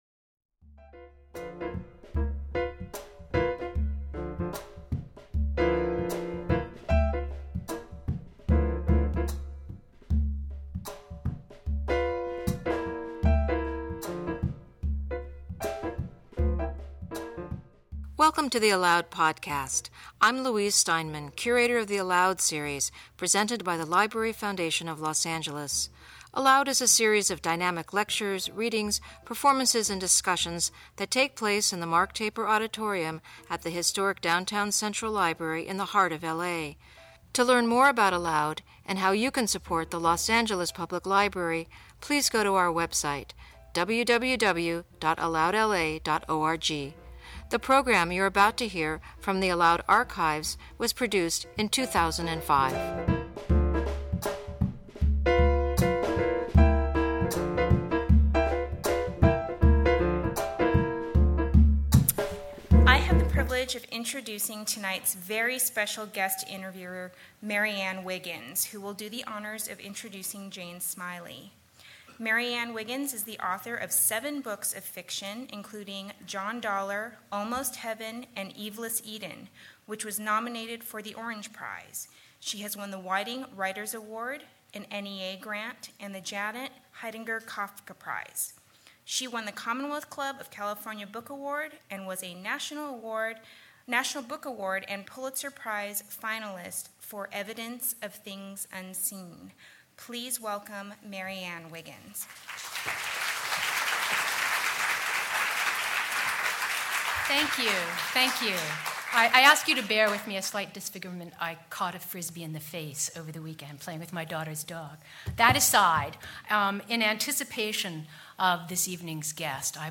Jane Smiley In Conversation With Novelist Marianne Wiggins